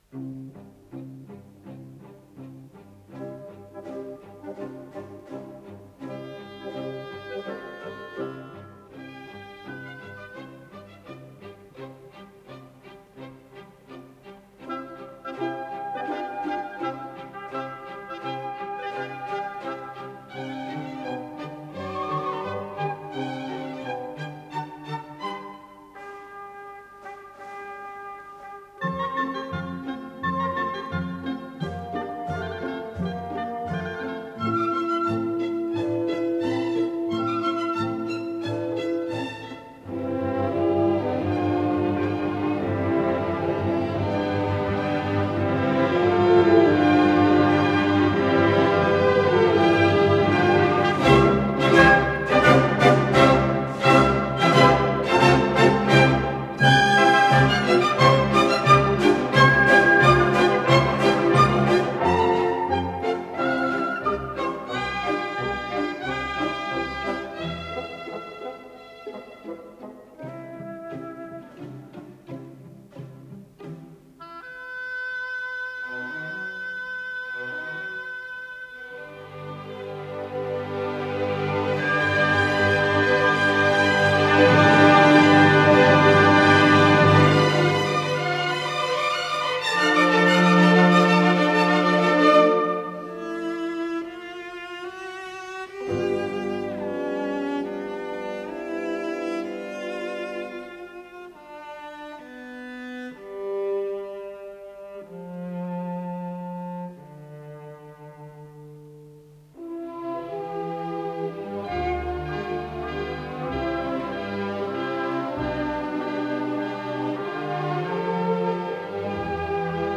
с профессиональной магнитной ленты
ИсполнителиСимфонический оркестр Всесоюзного радио и Центрального телевидения
Дирижёр - Константин Иванов
ВариантДубль моно